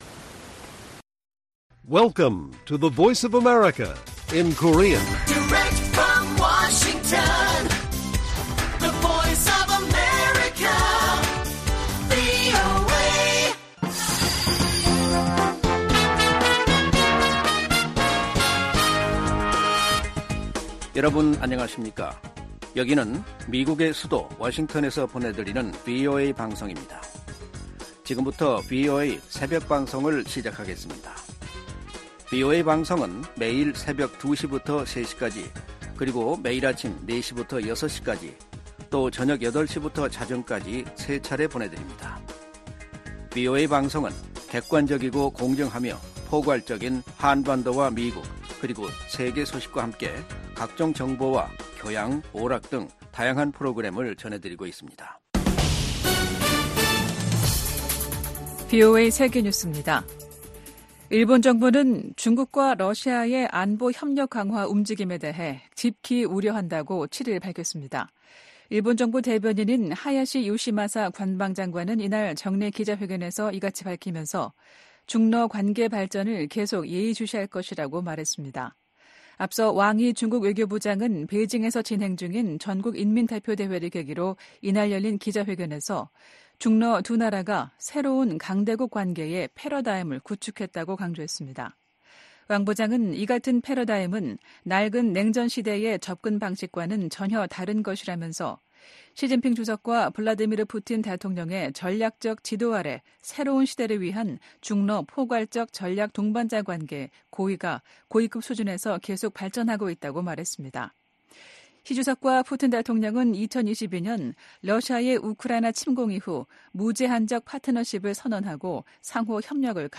VOA 한국어 '출발 뉴스 쇼', 2024년 3월 8일 방송입니다. 김정은 북한 국무위원장이 서부지구 작전훈련 기지를 방문해 전쟁준비 완성과 실전훈련 강화를 강조했다고 관영 매체들이 보도했습니다. 미국 정부는 현재 진행 중인 미한 연합훈련이 전쟁연습이라는 북한의 주장을 일축했습니다. 미국이 국제원자력기구(IAEA) 이사회에서 북한-러시아 탄도미사일 거래를 강력 규탄했습니다.